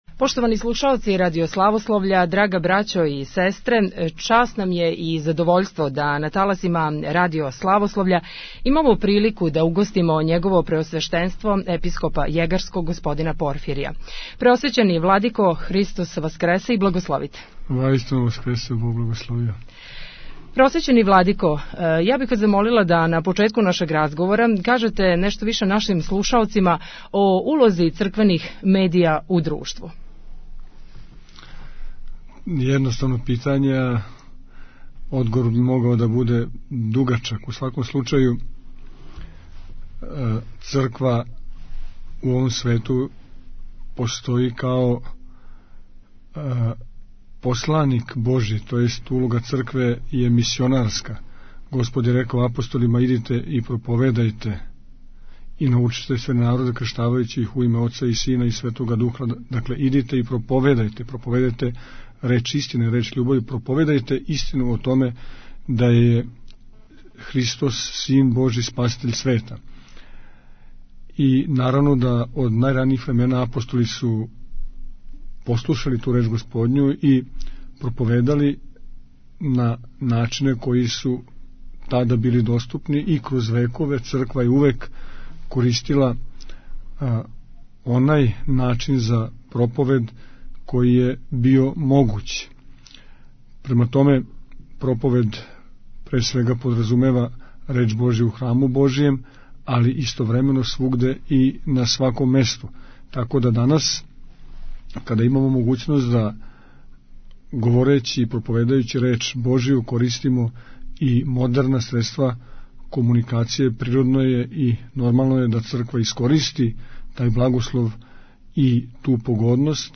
Звучни запис интервјуа са Епископом Порфиријем
Vladika Porfirije - Intervju 25-04.mp3